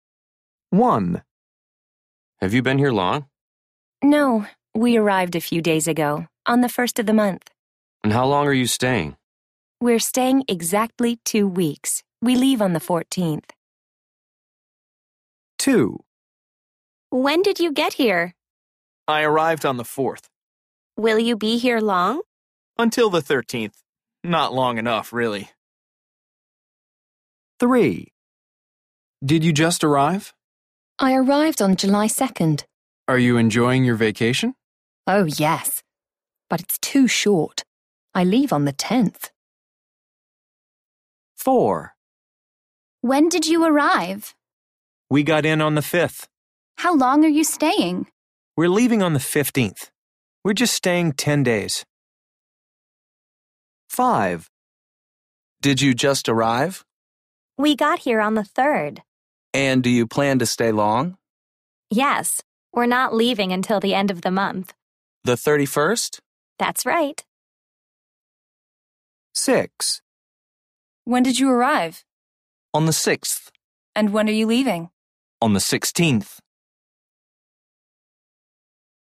People are talking to visitors. When di the visitors arrive and when will they leave?